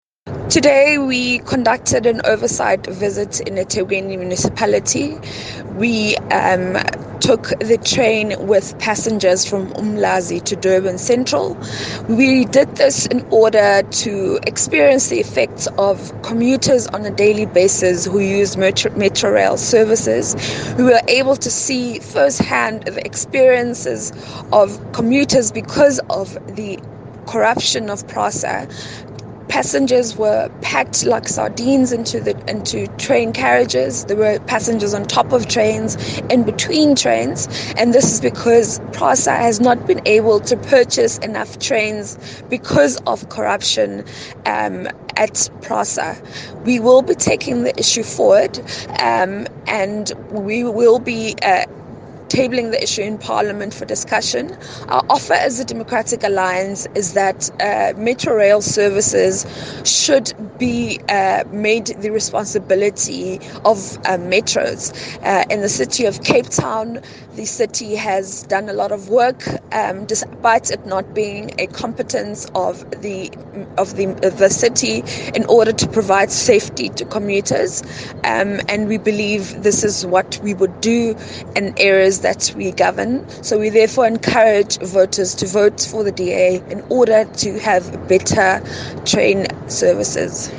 Issued by Phumzile Van Damme MP – DA Team One SA Spokesperson on Corruption
Please find attached soundbites in English by